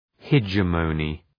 Προφορά
{hı’dʒemənı}